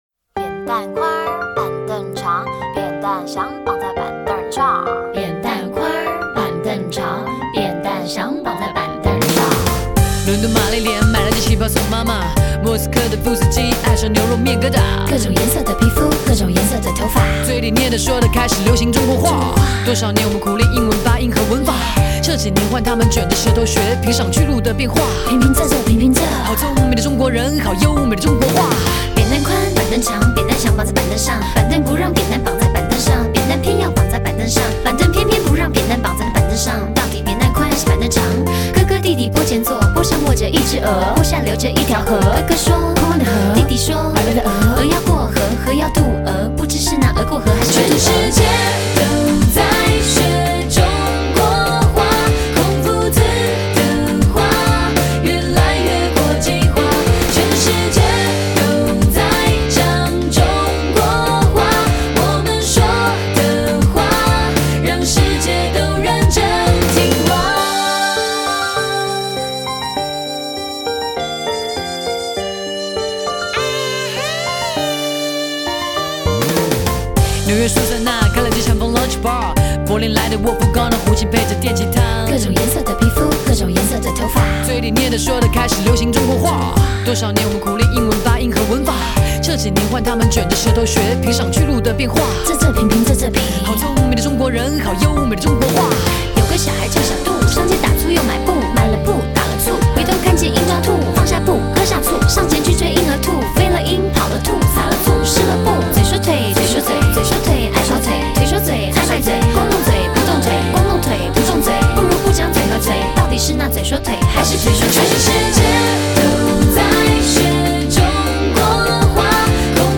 揉合中国绕口令及西方饶舌创意于一曲